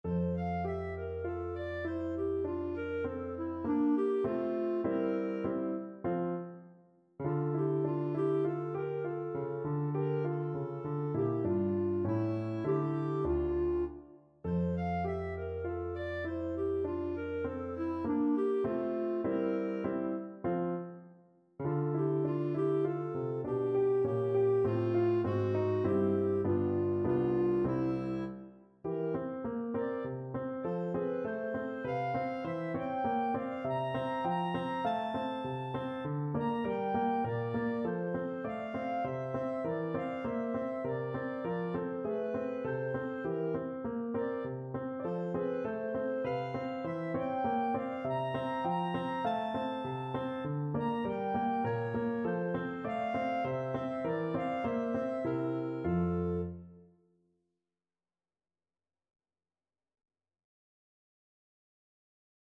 Clarinet
F major (Sounding Pitch) G major (Clarinet in Bb) (View more F major Music for Clarinet )
3/4 (View more 3/4 Music)
Allegretto Tranquillo
D5-Bb6
Classical (View more Classical Clarinet Music)